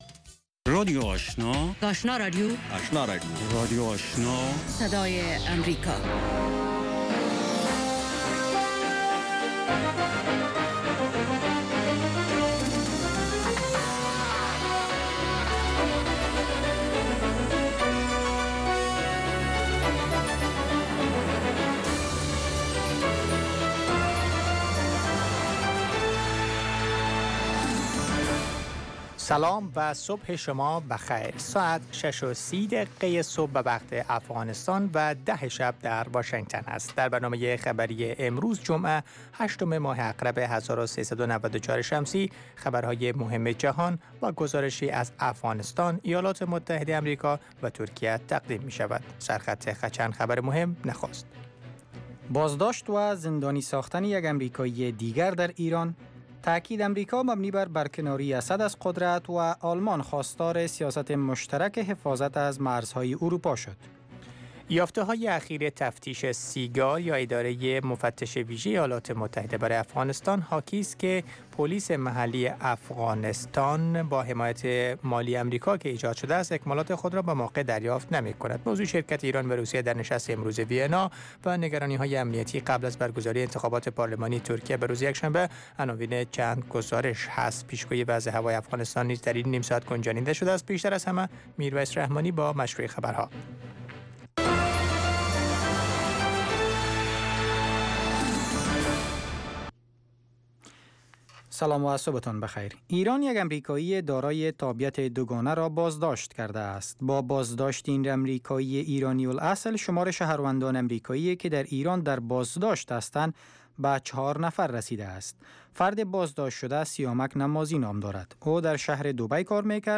دومین برنامه خبری صبح، حاوى تازه ترين خبرهاى افغانستان و جهان است. این برنامه، همچنین شامل گزارش هایی از افغانستان، ایالات متحده امریکا و مطلب مهمی از جهان می باشد. پیش گویی وضع هوای افغانستان و چند رویداد ورزشی از جهان نیز شامل این برنامه است.